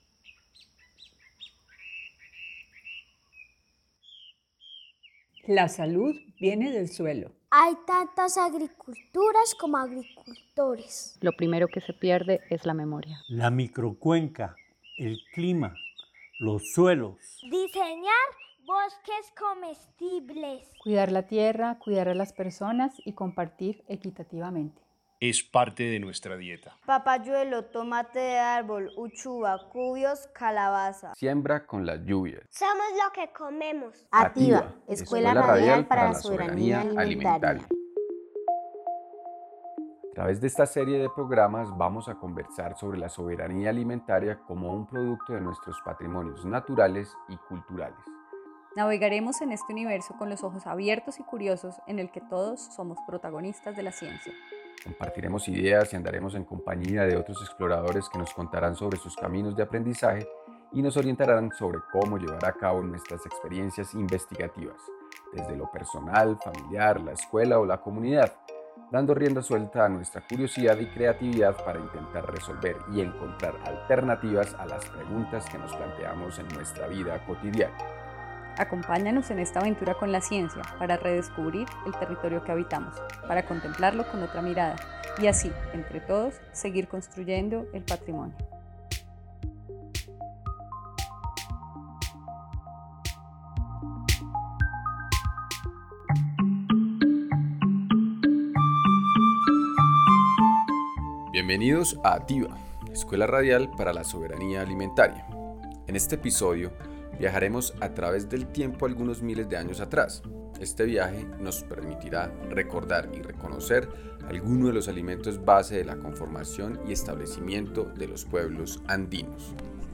Programa radial capítulo siete.